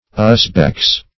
Search Result for " usbeks" : The Collaborative International Dictionary of English v.0.48: Usbegs \Us"begs\, Usbeks \Us"beks\, n. pl.